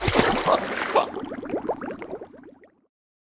Block Player Drown Sound Effect
Download a high-quality block player drown sound effect.
block-player-drown.mp3